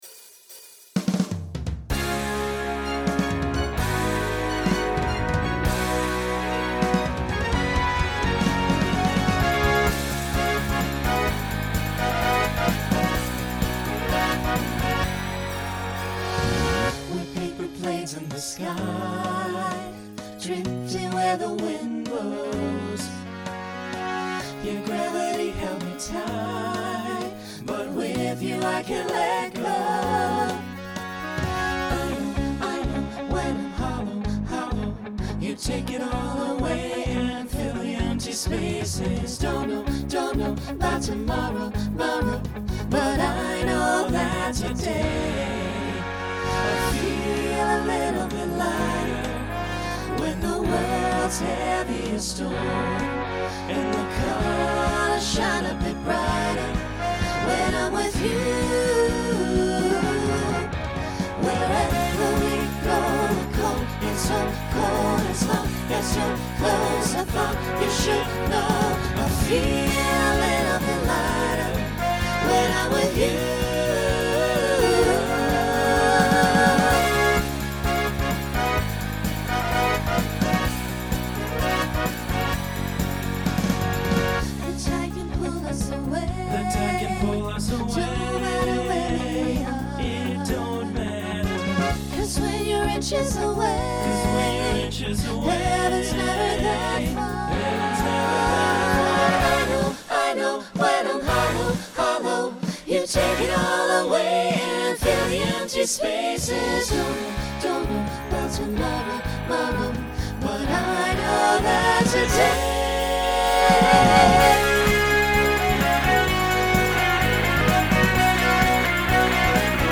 Genre Pop/Dance
Opener Voicing SATB